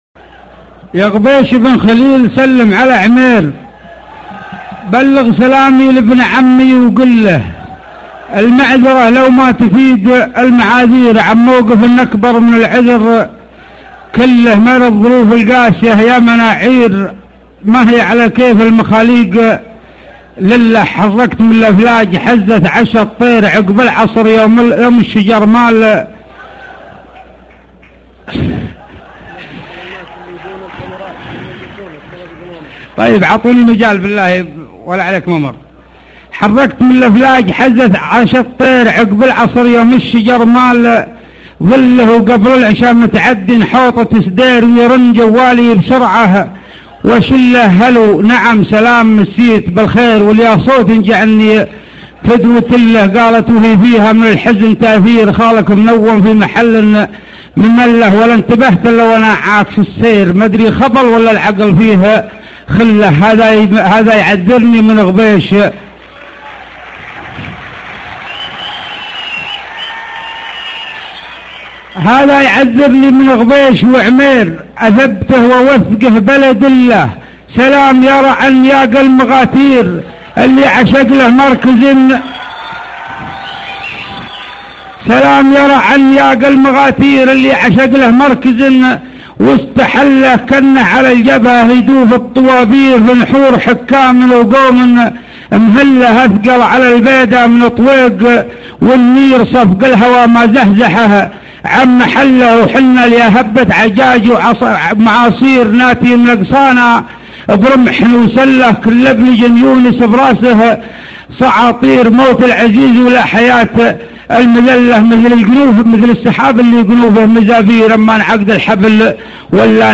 ياغبيش-امسية مزاين قحطان